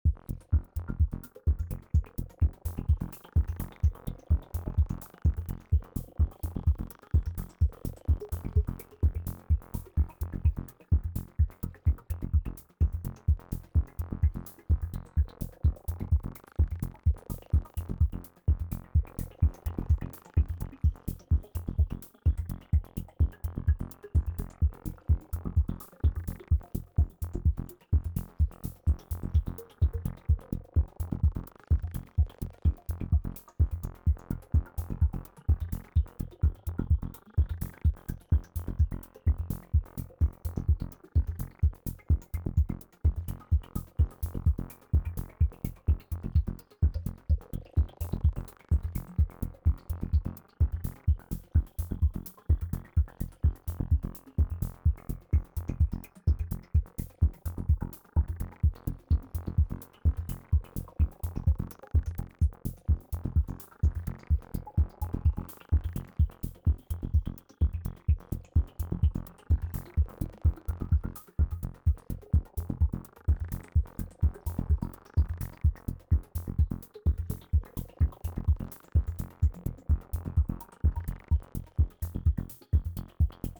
Here’s for SWARM : a deep techno/cosmic trance combo, really like that bass and melodic line !